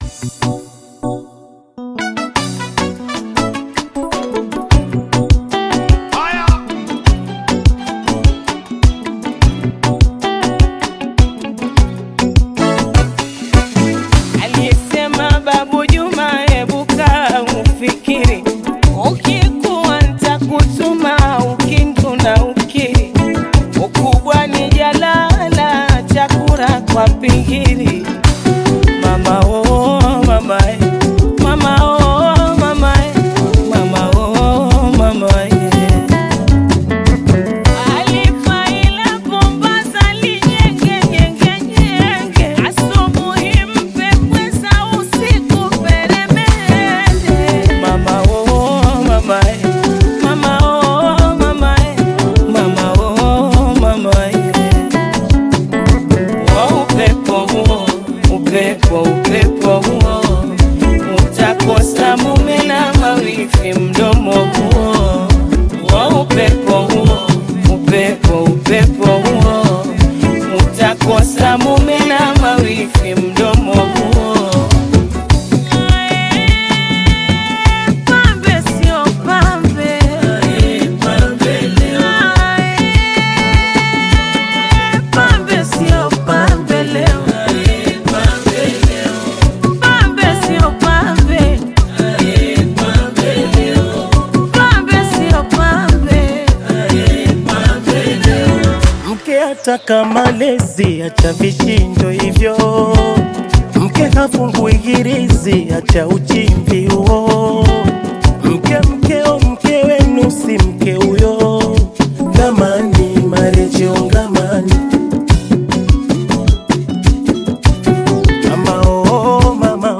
Taarab music track
taarab singer